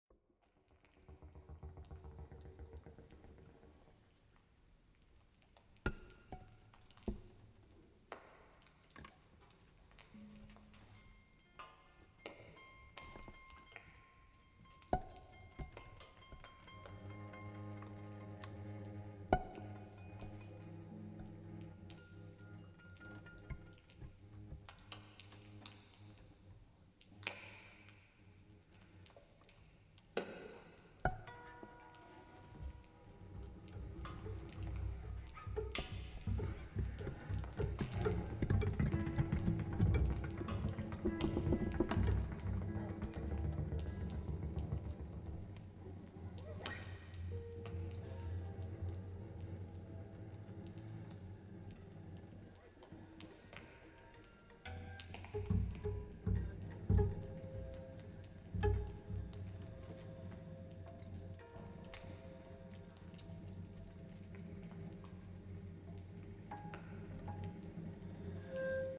recorded October 1, 2024 in Jordan Hall, Boston